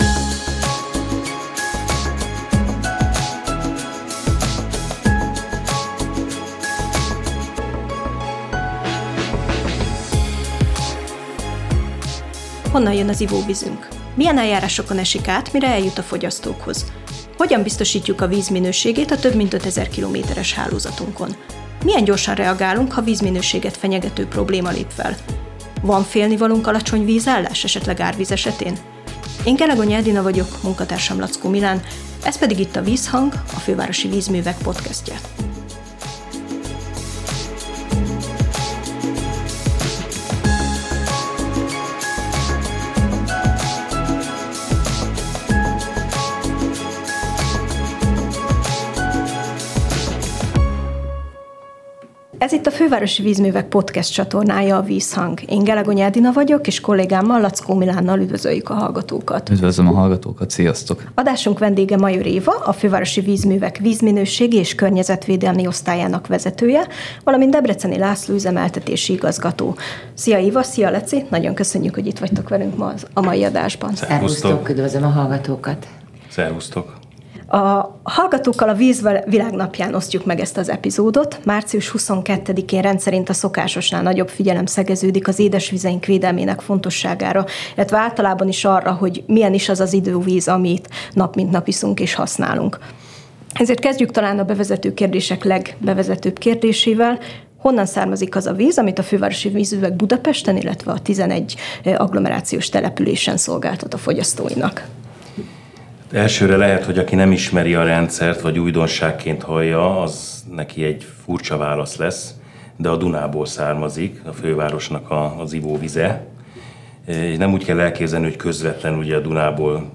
Víztermelés, vízellátás, vízminőség – Interjú